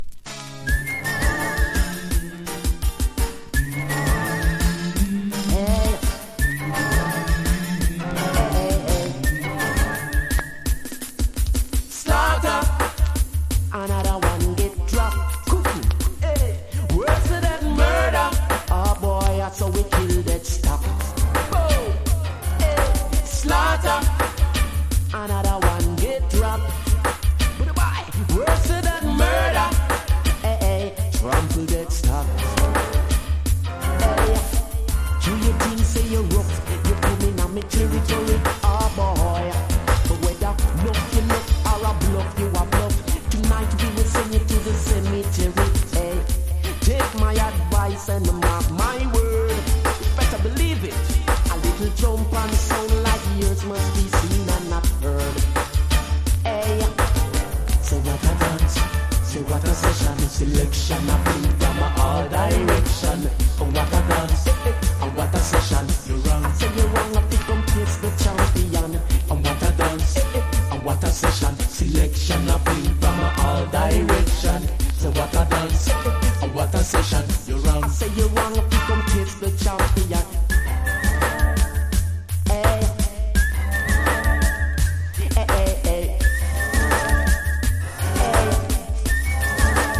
• REGGAE-SKA
形式 : 7inch / 型番 : / 原産国 : JAM
DANCE HALL CLASSIC!!
# DANCE HALL
所によりノイズありますが、リスニング用としては問題く、中古盤として標準的なコンディション。